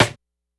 Snare_17.wav